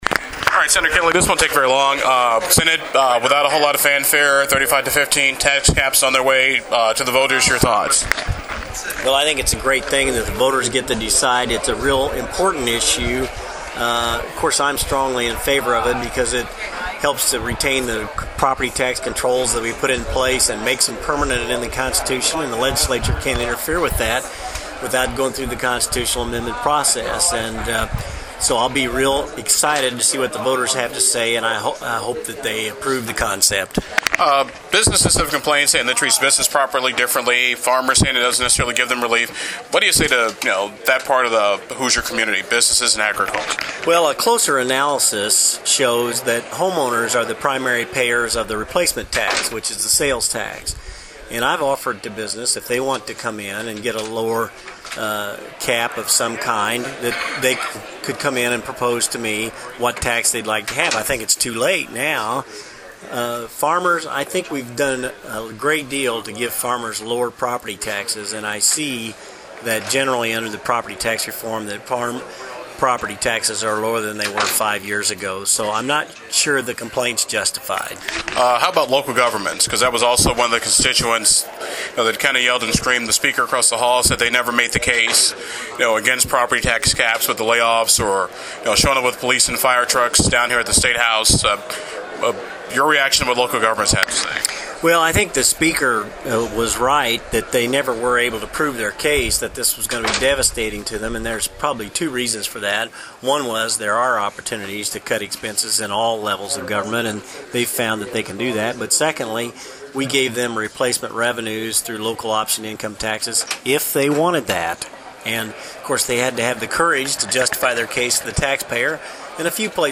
If passed into the Constitution, property taxes would be capped at 1-percent of a home’s value for residential property, 2 percent for rental and 3 percent for residential.   Below is my afternoon interview with Sen.